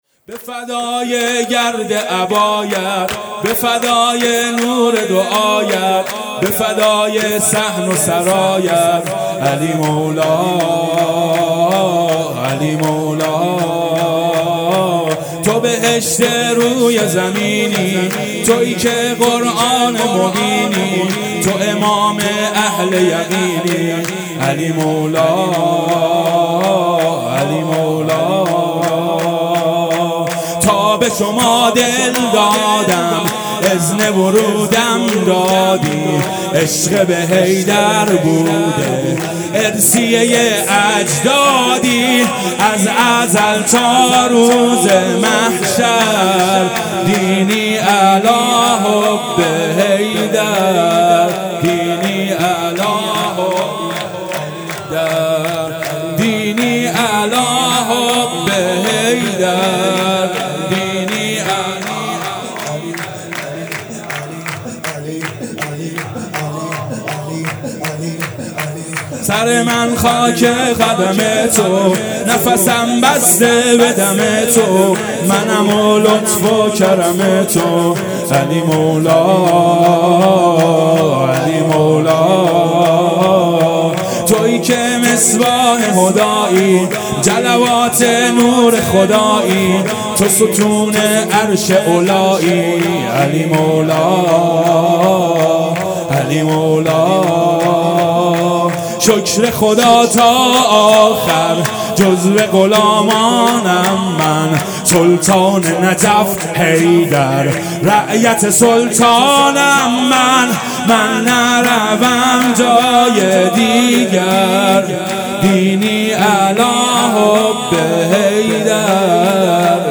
بخش‌دوم-سرود